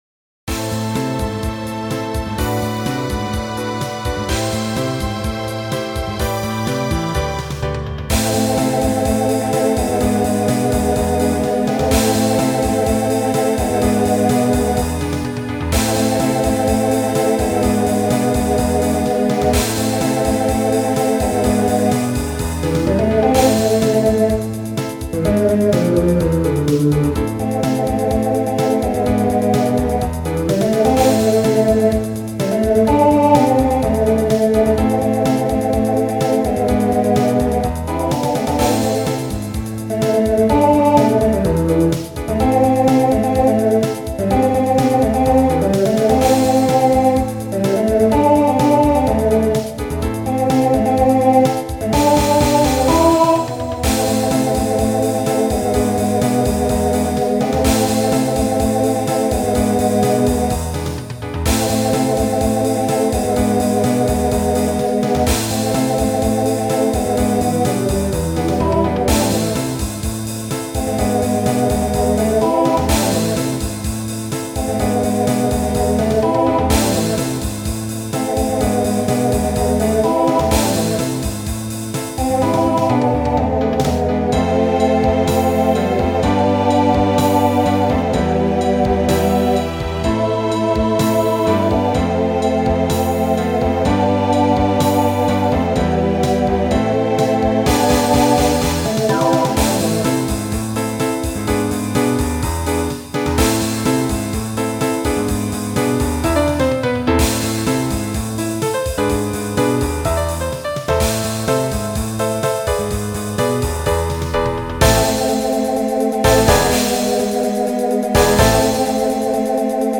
Voicing TTB Instrumental combo Genre Pop/Dance